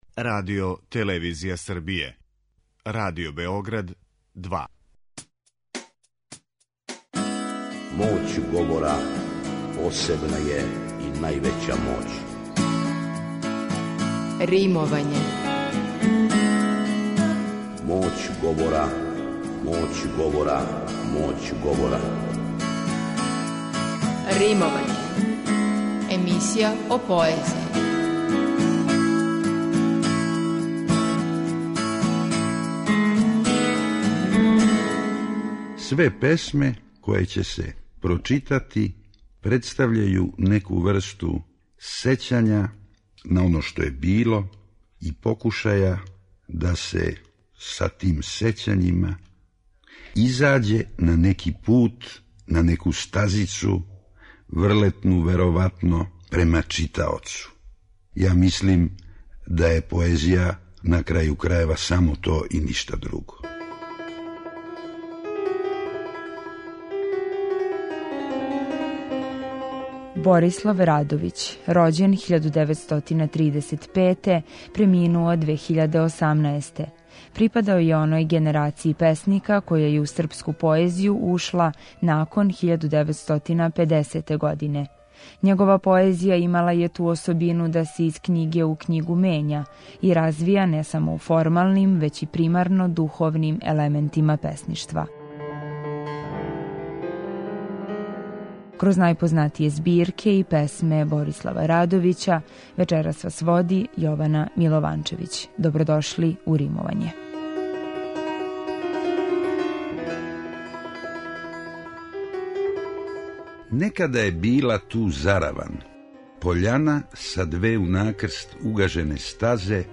У Римовању слушамо поезију песника Борислава Радовића